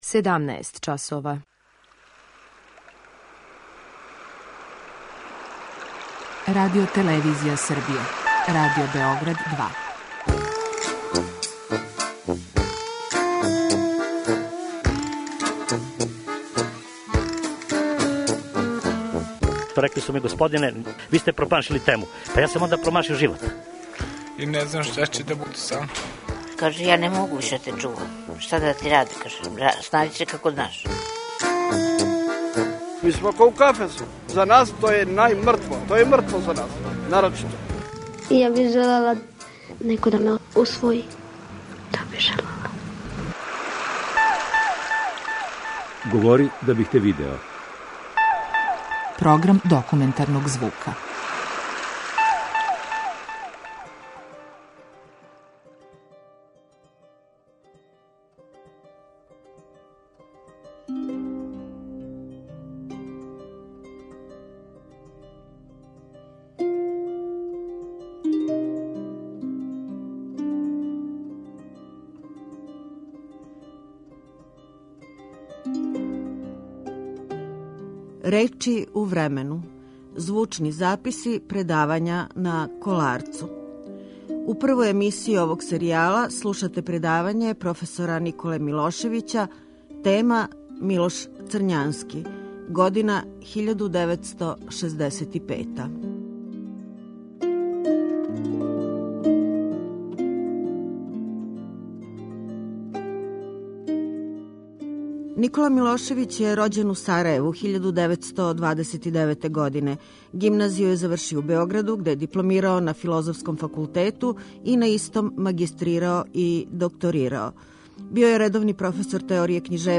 У термину емисије 'Говори да бих те видео', почињемо са емитовањем серијала 'РЕЧИ У ВРЕМЕНУ - звучни записи предавања са Коларца'.
Данас имате прилику да чујете професора Николу Милошевића и предавање под насловом "Милош Црњански", одржано 27.10.1965. године. Драгоцено је што се може чути и последњи одломак из романа "Сеобе" који говори лично Црњански.